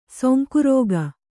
♪ sonku rōga